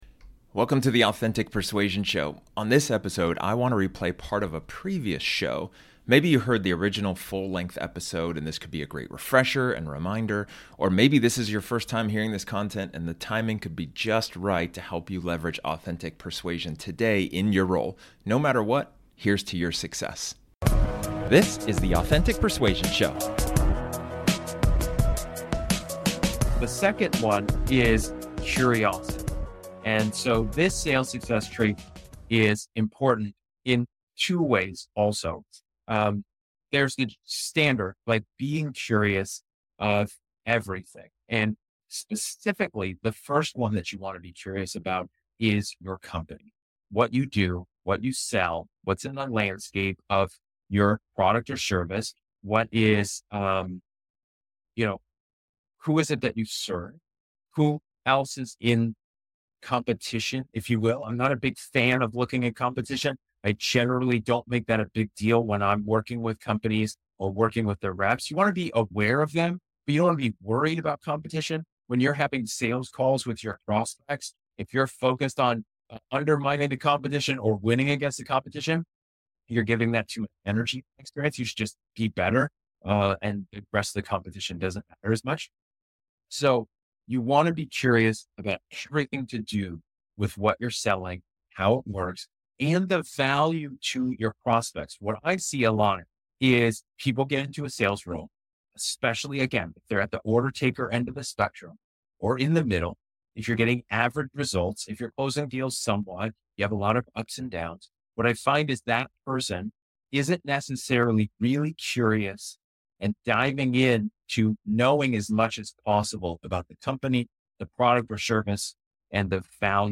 This episode is an excerpt from one of my training sessions where I talk about one of the 5 Sales Success Traits.